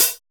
LINN CHH 2.wav